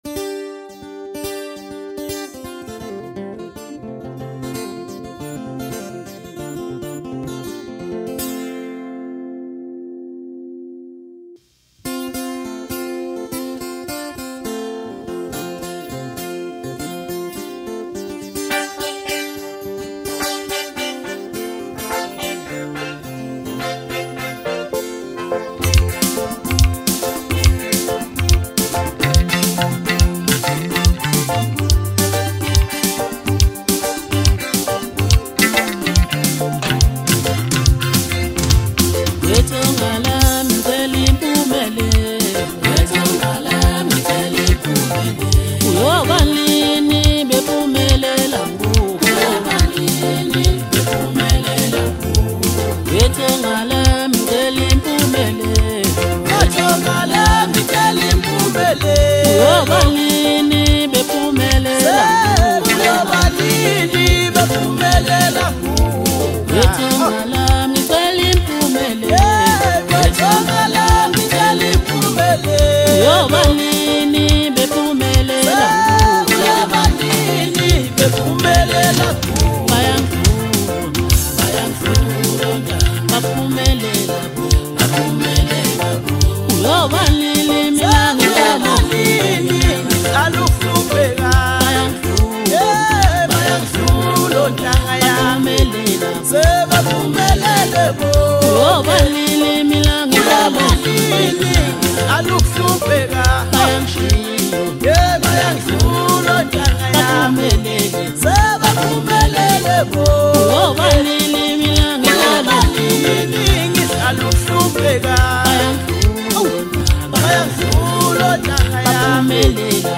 Maskandi, Hip Hop